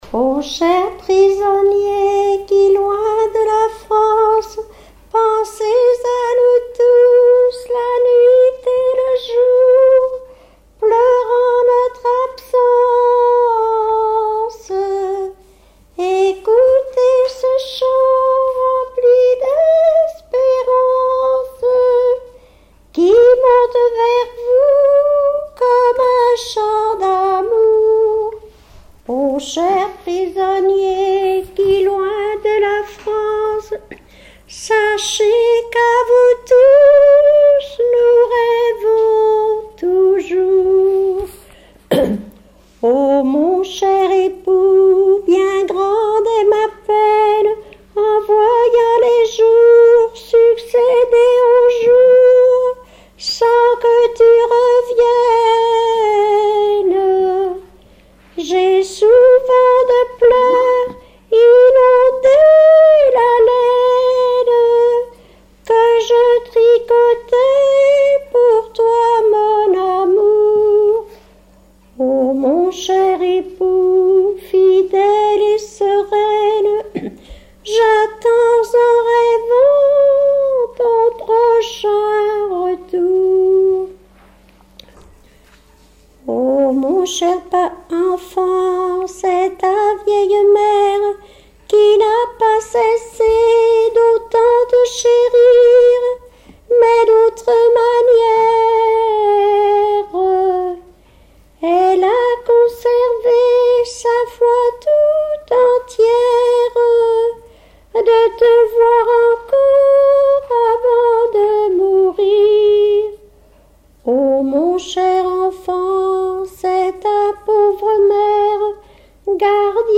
Témoignages et chansons
Pièce musicale inédite